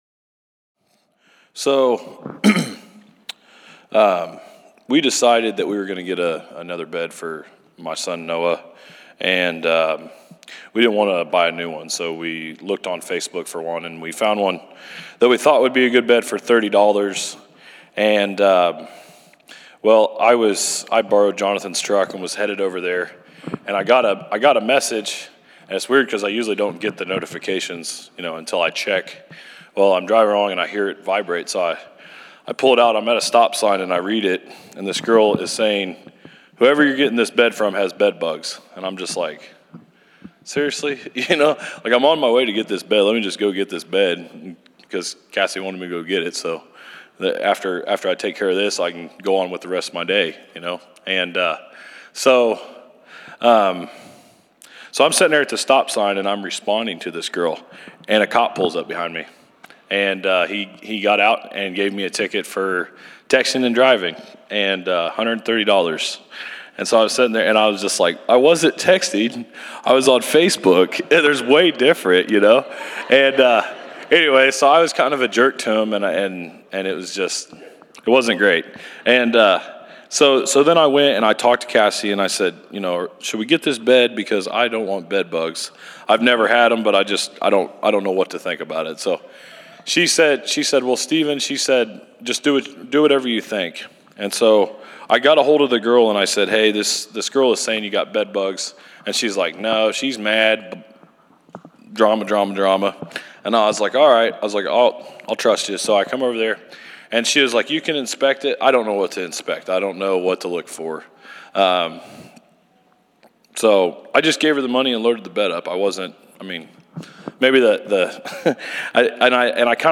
February 21, 2016      Category: Testimonies      |      Location: El Dorado